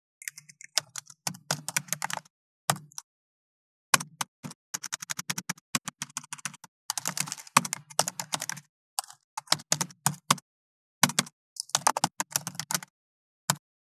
37.タイピング【無料効果音】
ASMRタイピング効果音
ASMR